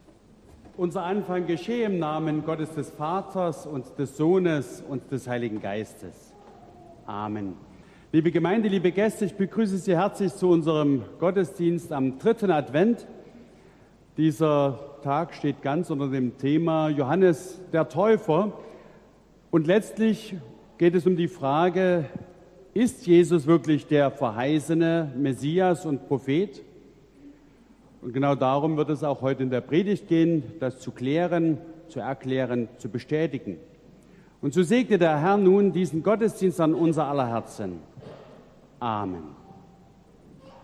Gottesdienst am 11.12.2022
Audiomitschnitt unseres Gottesdienstes vom 3.Advent 2022.